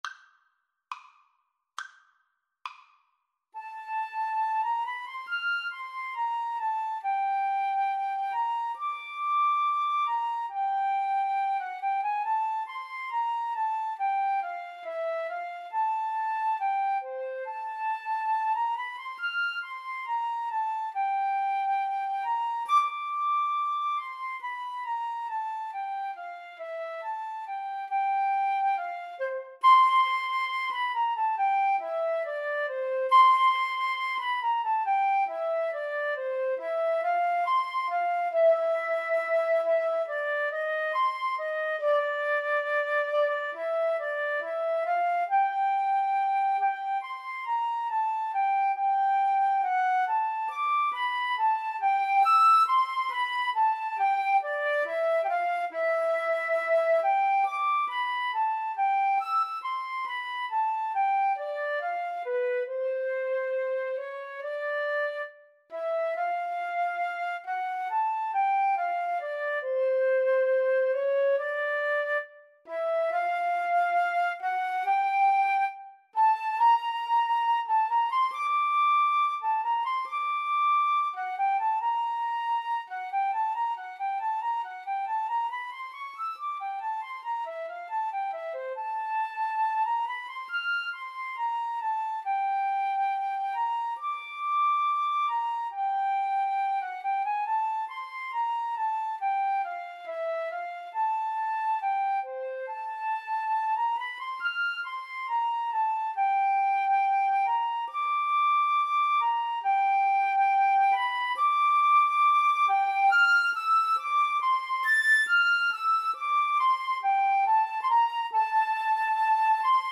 Free Sheet music for Flute Duet
F major (Sounding Pitch) (View more F major Music for Flute Duet )
~ = 69 Allegro grazioso (View more music marked Allegro)
2/4 (View more 2/4 Music)
Classical (View more Classical Flute Duet Music)